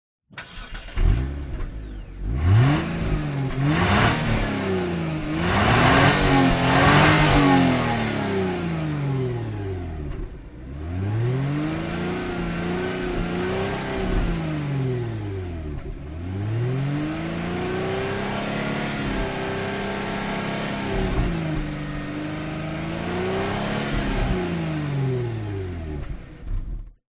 Звуки выхлопной системы